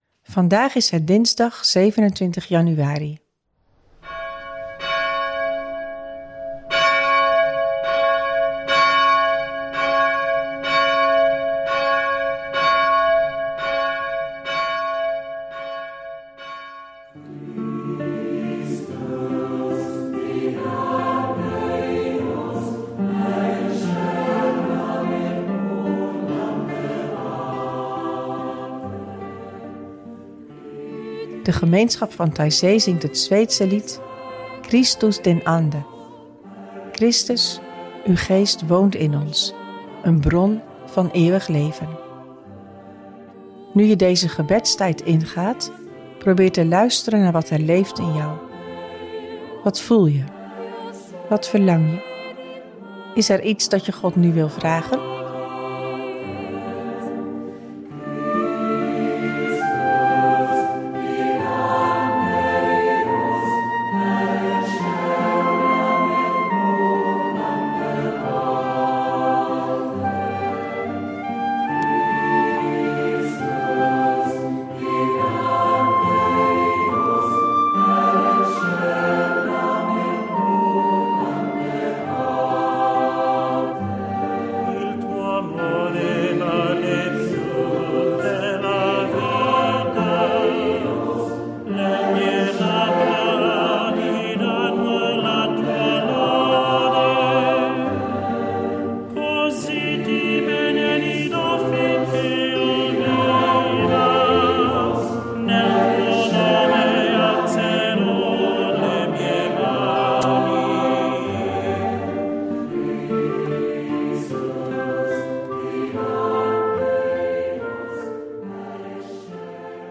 De muzikale omlijsting, overwegingen y begeleidende vragen helpen je om tot gebed te komen.